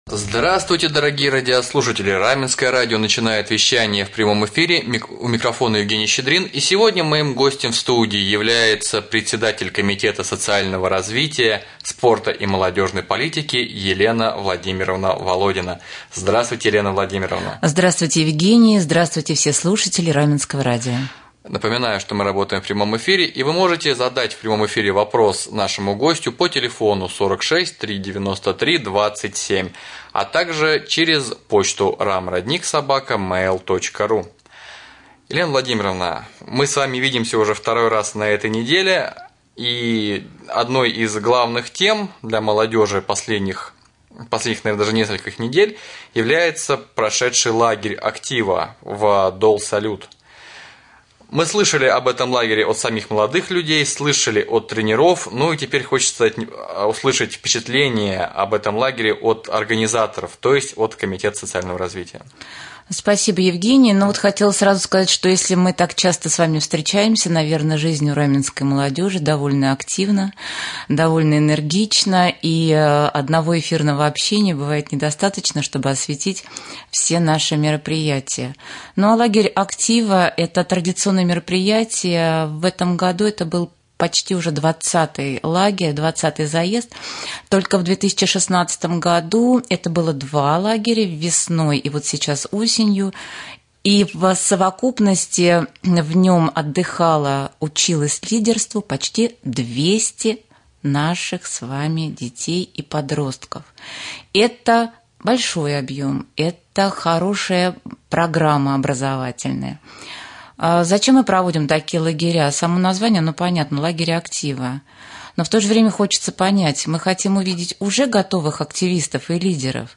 Прямой эфир с председателем Комитета социального развития, спорта и молодежной политики Еленой Володиной